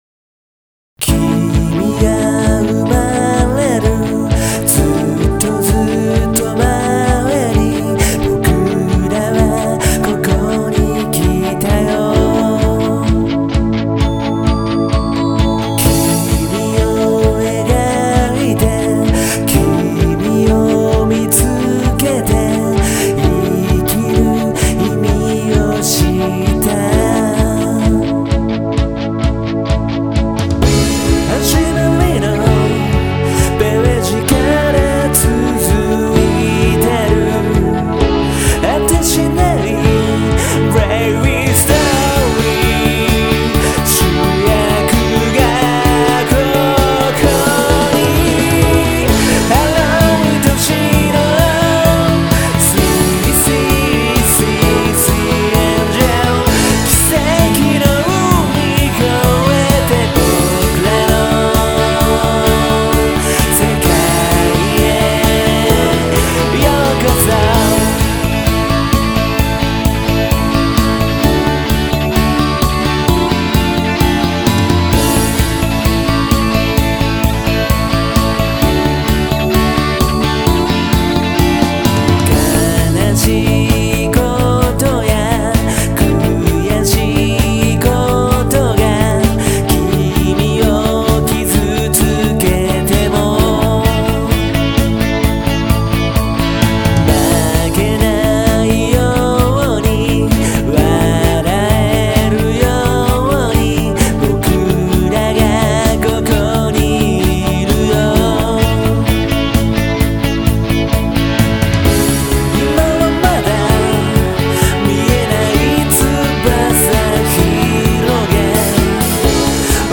これまでのポップパンクがめっちゃポップになる。